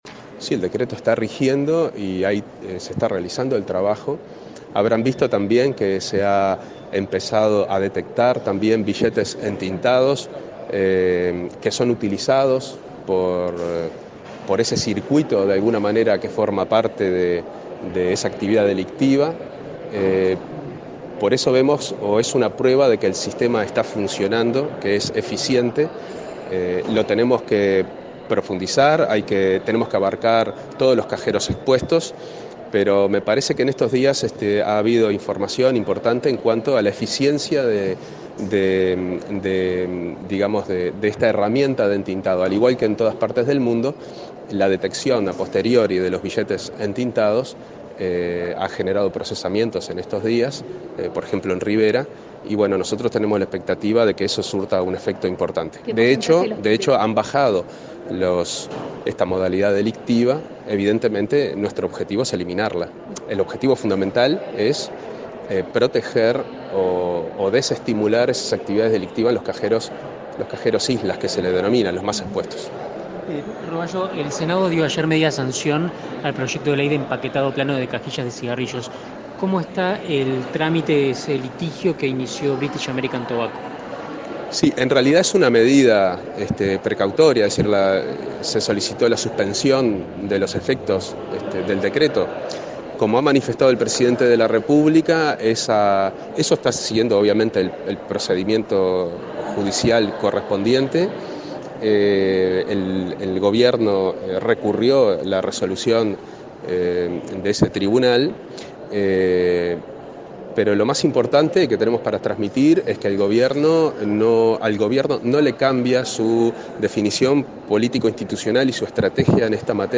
Consultado por la prensa sobre temas de actualidad, habló de la economía uruguaya y su reconocimiento a nivel mundial y de la determinación del Gobierno de continuar con su lucha contra el consumo de tabaco.